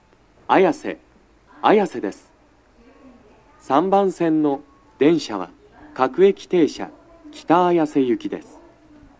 足元注意喚起放送の付帯は無いですが、0番線以外は、先発の北綾瀬行き発車ホームの案内などの駅員放送が、大変被りやすいです
男声
到着放送1